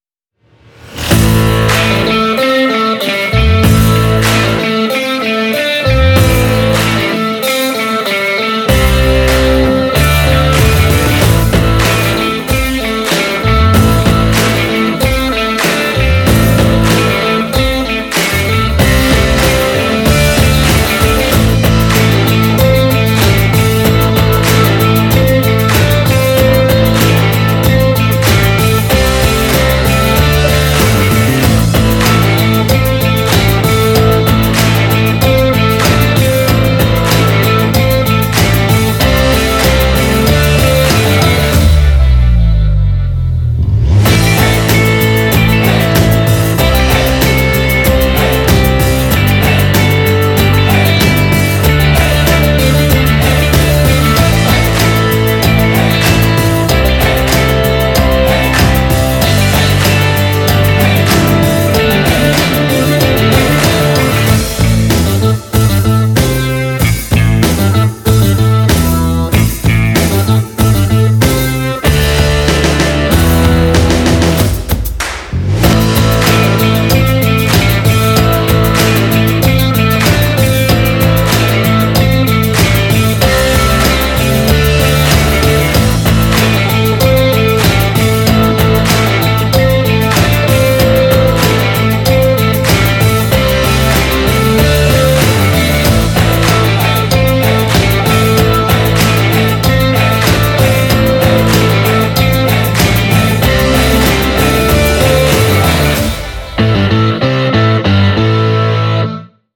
Genre: hardrock, rock.